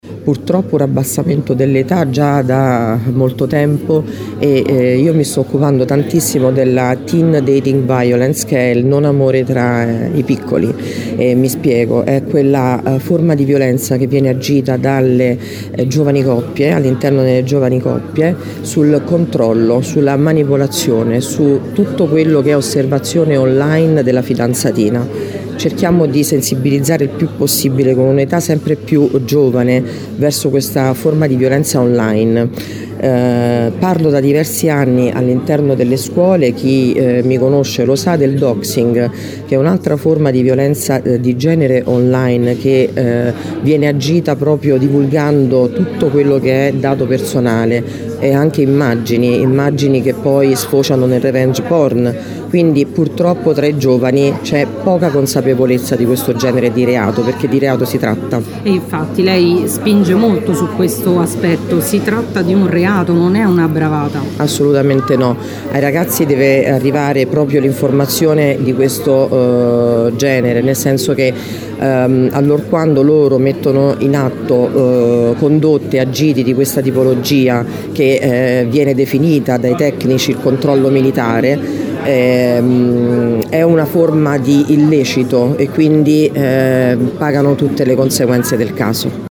L’evento, che precede la Giornata Internazionale per l’eliminazione della violenza contro le donne, si è tenuto nella sala conferenze del Polo Pontino della Sapienza con la partecipazione di molti attori istituzionali.
Le interviste contenute in questo articolo
MONICA SANSONI GARANTE PER L’INFANZIA E L’ADOLESCENZA DEL LAZIO